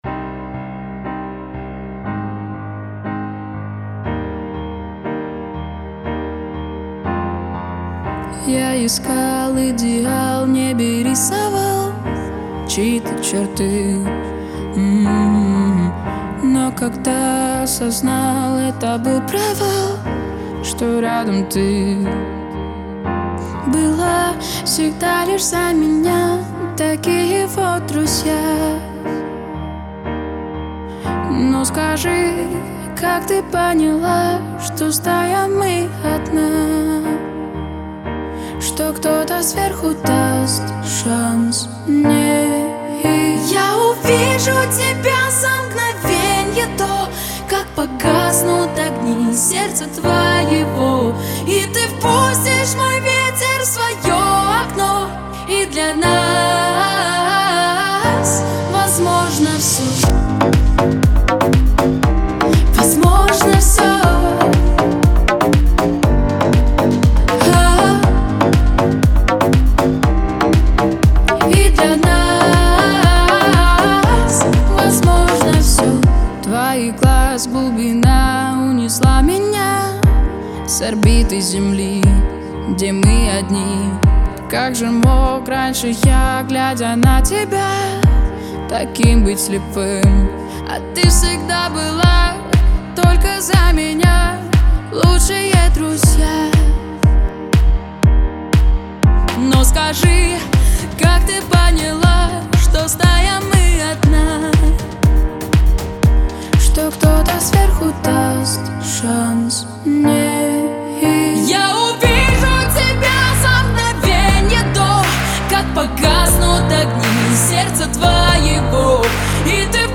• Жанр: Детские песни
подростковые песни